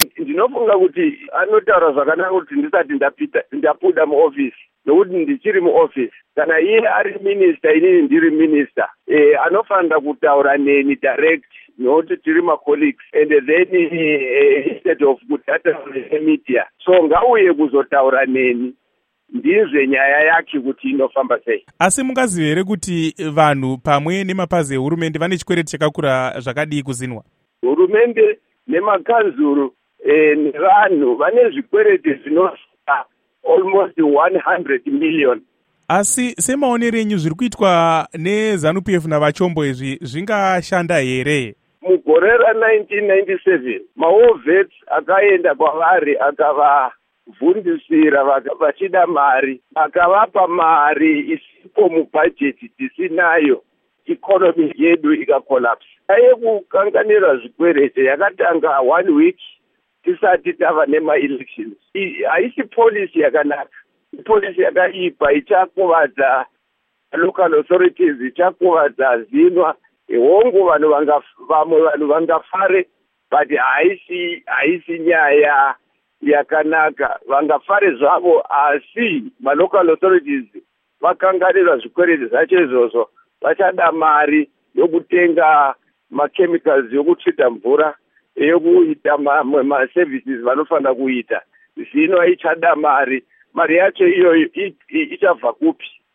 Hurukuro naVaSamuel Sipepa Nkomo